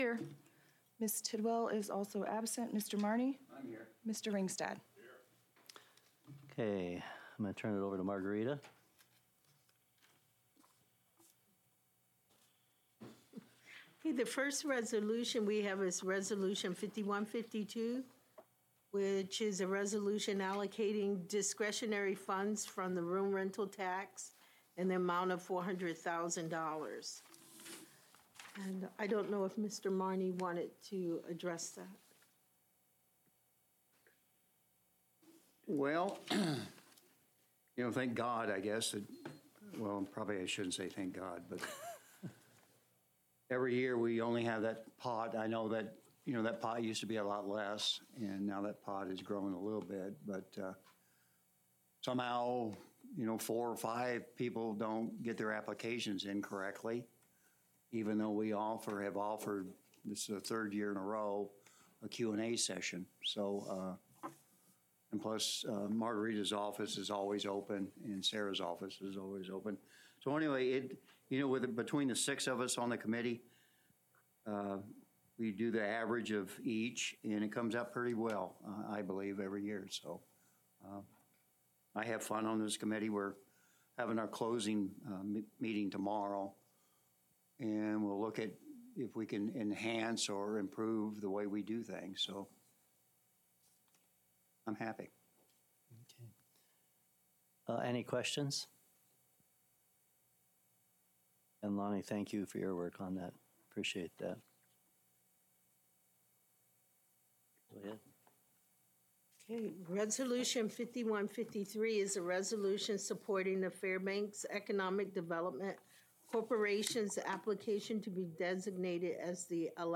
Regular City Council Work Session